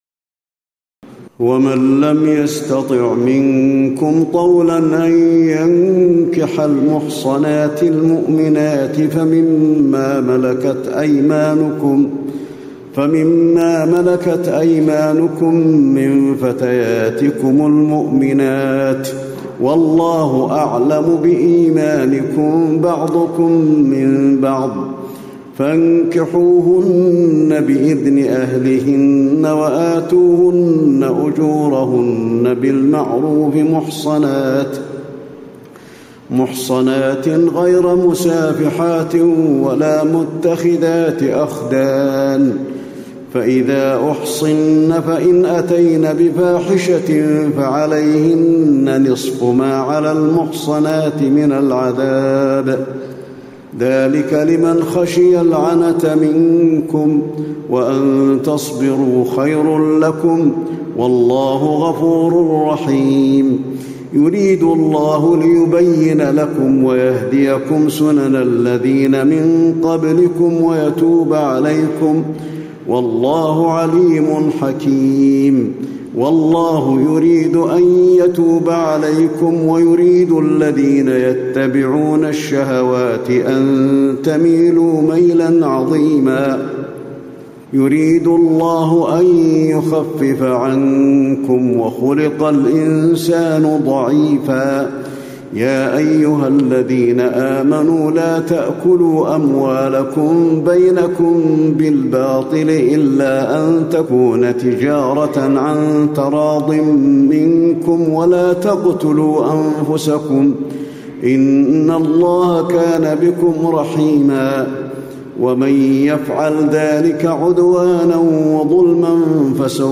تراويح الليلة الخامسة رمضان 1436هـ من سورة النساء (25-87) Taraweeh 5 st night Ramadan 1436H from Surah An-Nisaa > تراويح الحرم النبوي عام 1436 🕌 > التراويح - تلاوات الحرمين